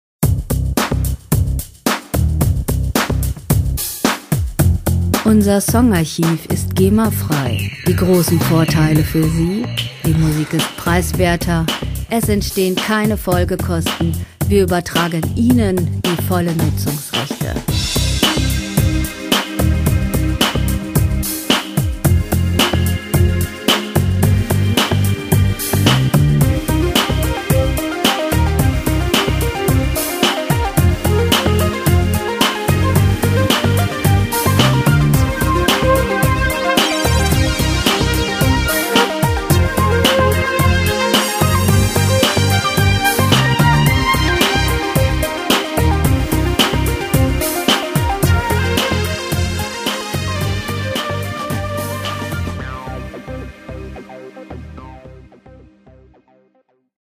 Musikstil: Pop
Tempo: 110 bpm
Tonart: C-Moll
Charakter: erotisch, kühl
Instrumentierung: Synthesizer, Schlagzeug